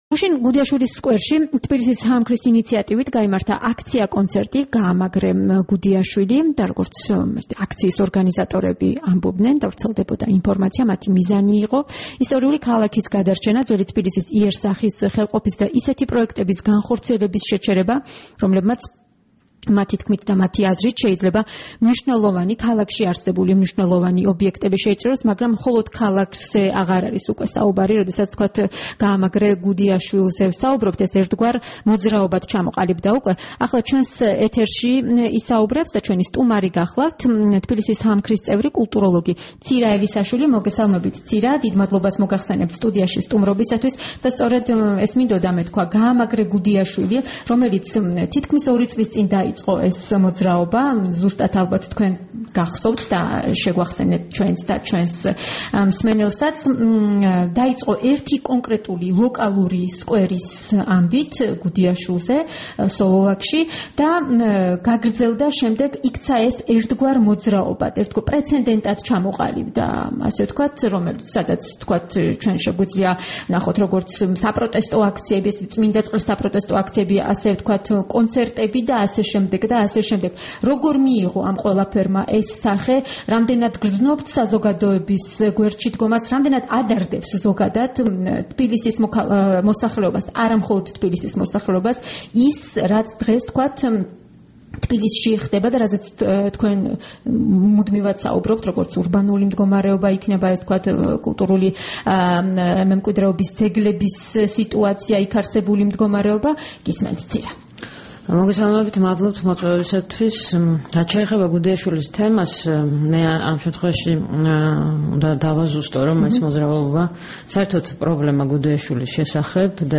სტუმრად ჩვენს ეთერში